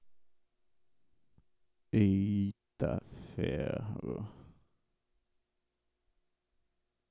Recording WAVE 'opa.wav' : Signed 16 bit Little Endian, Rate 8000 Hz, Mono
Reparou que a qualidade parece bem melhor? Esse foi o salto de 8bits/8000Hz pra 16bits/11025Hz.